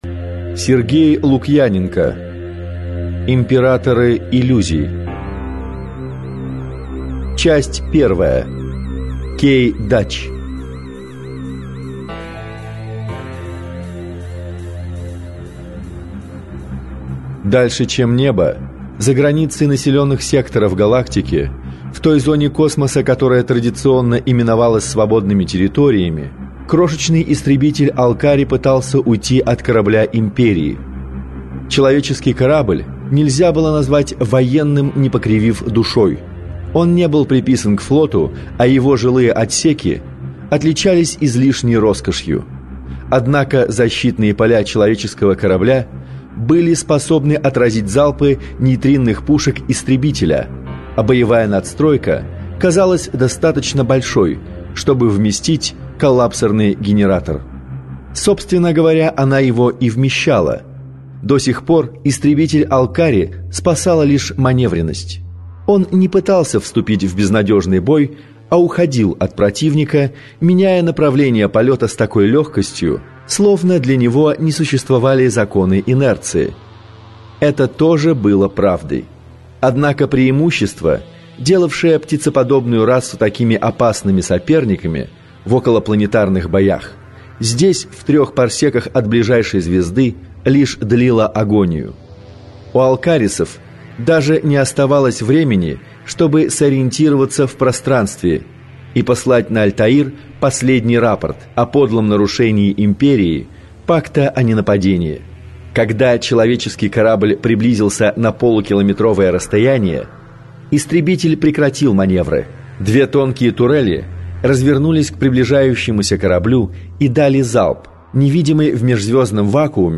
Аудиокнига Императоры иллюзий - купить, скачать и слушать онлайн | КнигоПоиск